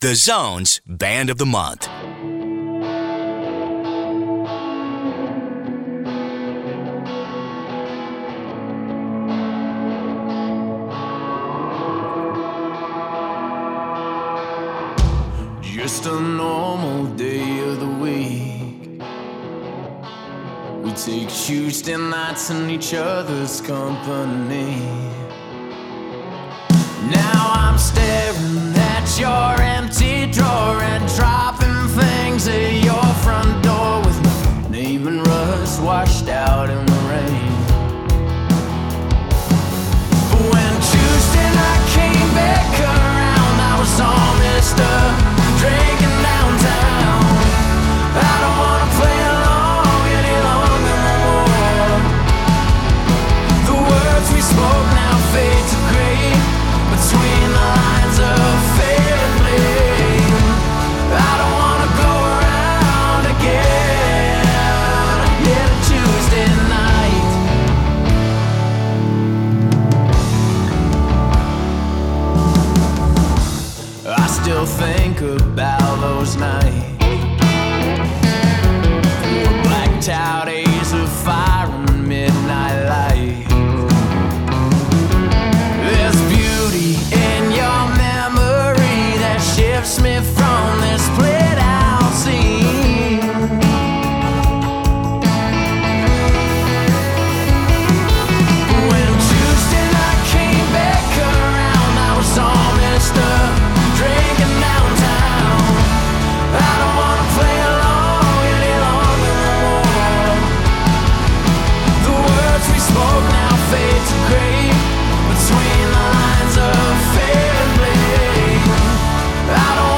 Lead Guitar & Lead Vocals
Drums
Keys, Guitar and Backing Vocals
Bass and Backing Vocals